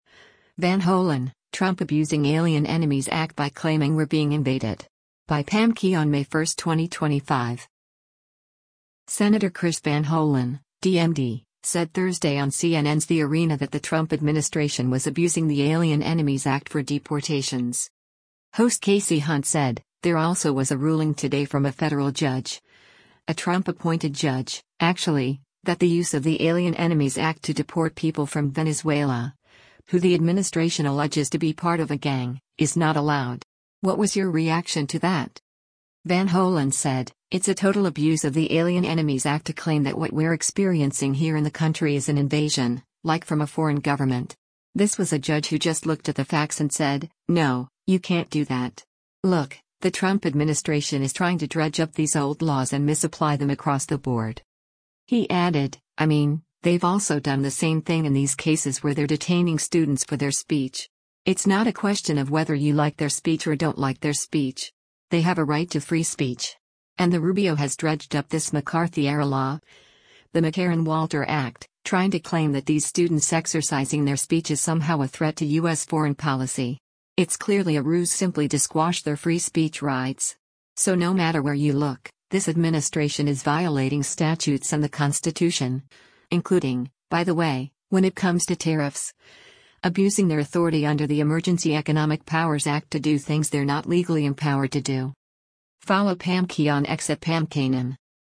Senator Chris Van Hollen (D-MD) said Thursday on CNN’s “The Arena” that the Trump administration was abusing the Alien Enemies Act for deportations.